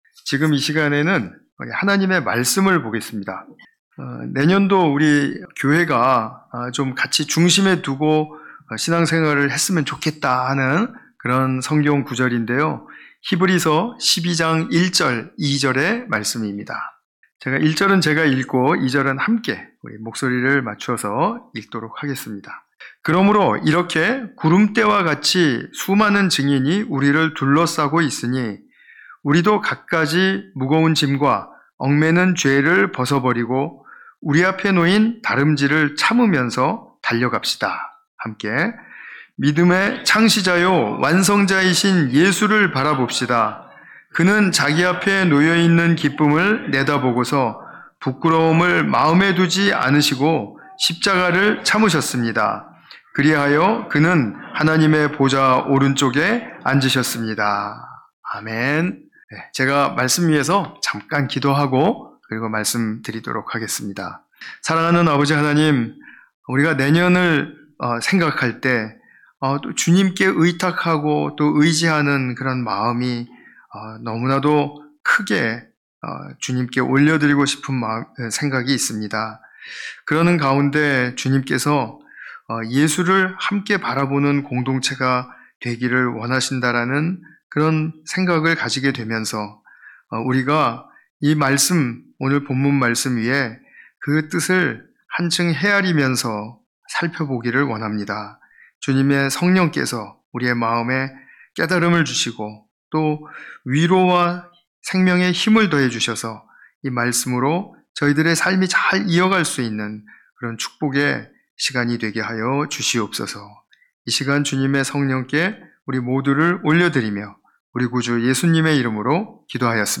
(송구영신예배) 예수를 바라보는 우리